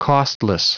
Prononciation du mot costless en anglais (fichier audio)
Prononciation du mot : costless